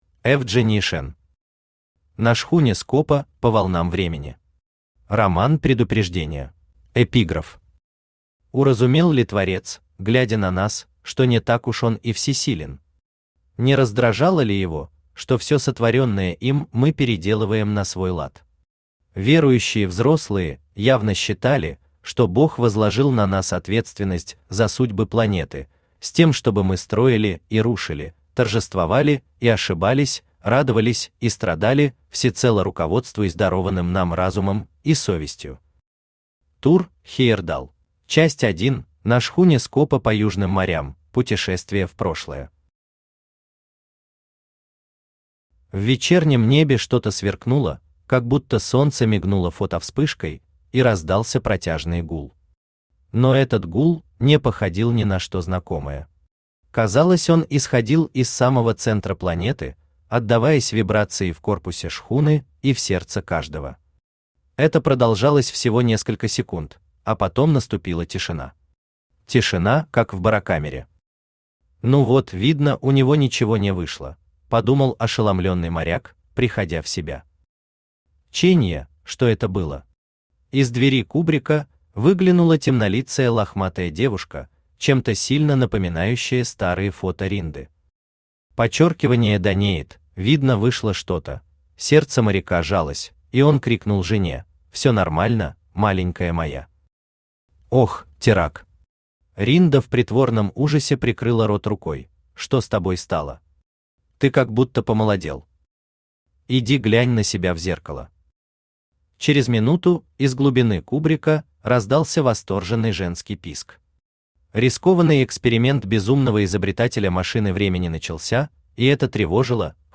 Роман-предупреждение Автор Evgenii Shan Читает аудиокнигу Авточтец ЛитРес.